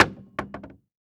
household
Cabinet Door Close